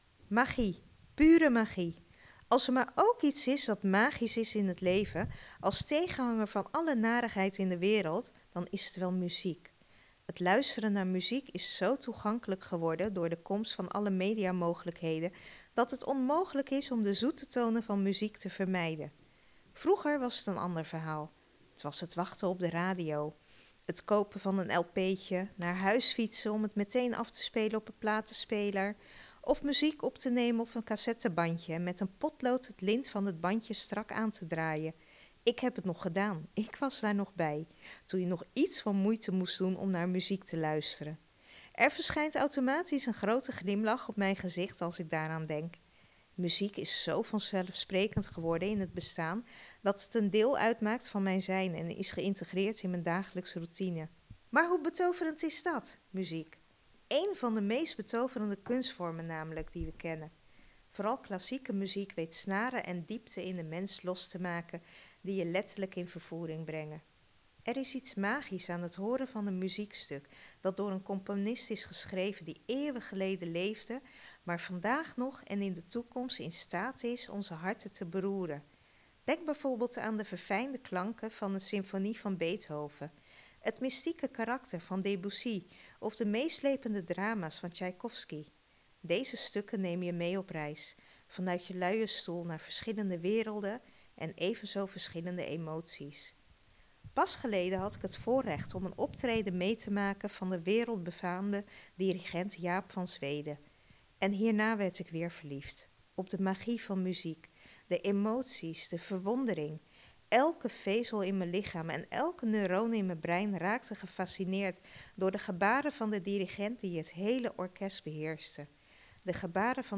Column